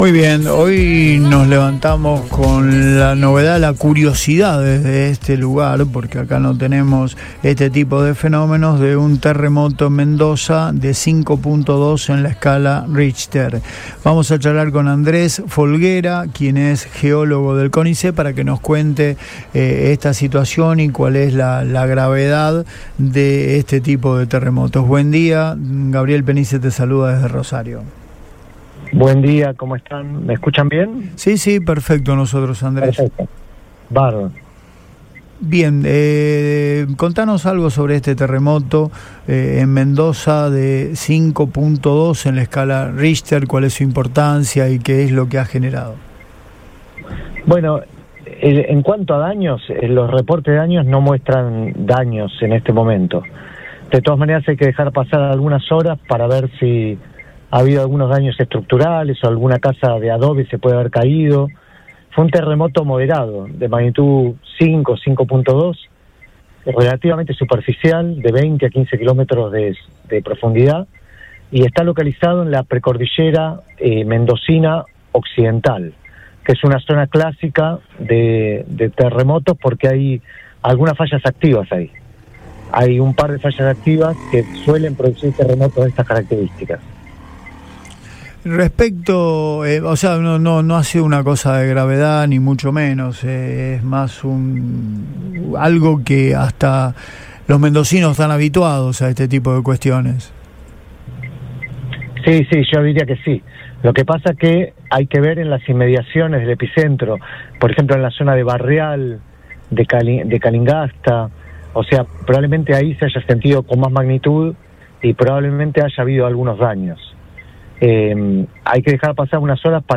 EN RADIO BOING